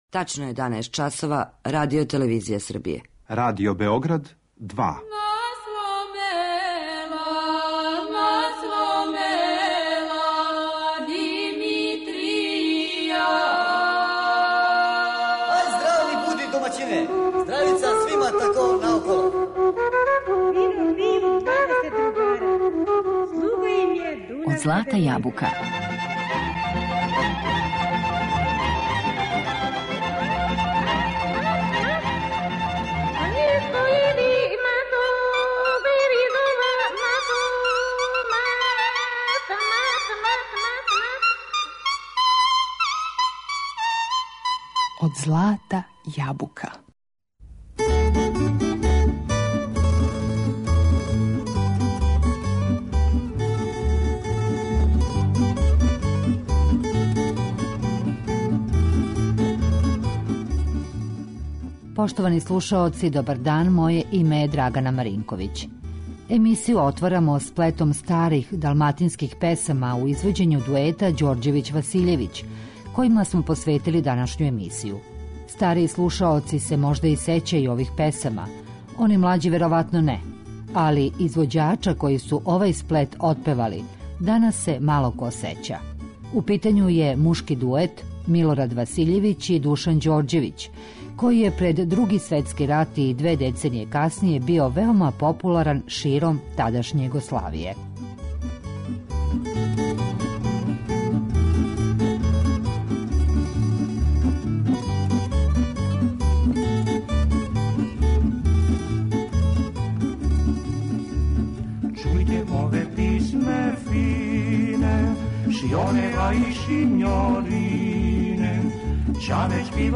Рођени су у Београду, а њихов репертоар су биле, тада популарне, далматинске песме. У Тонском архиву Радио Београда остало је тридесет трајних снимака овог дуета.